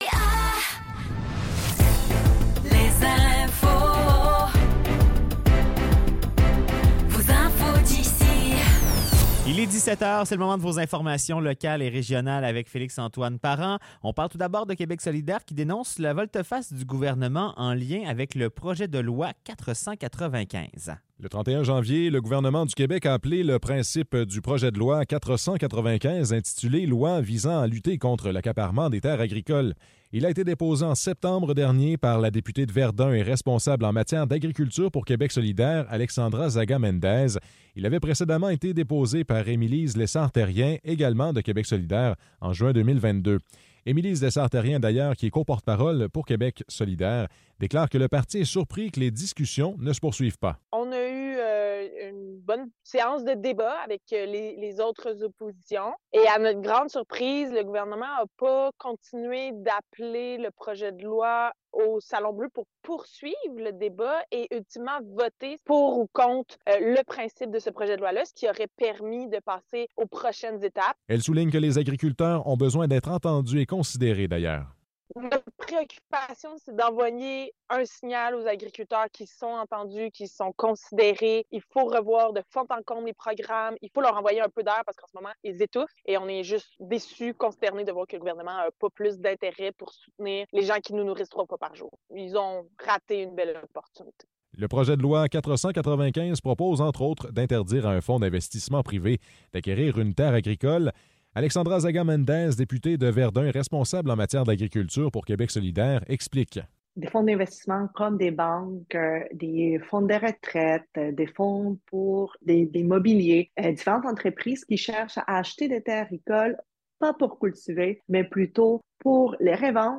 Nouvelles locales - 8 février 2024 - 17 h